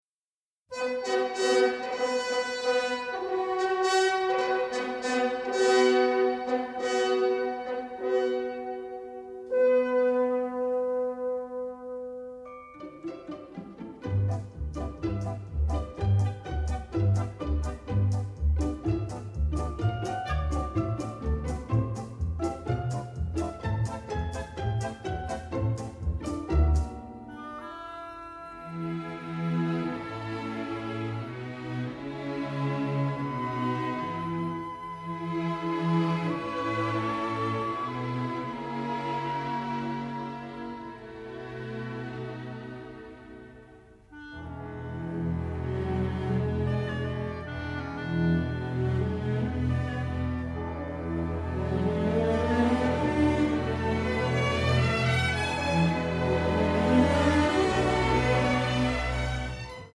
great exotica and pop tunes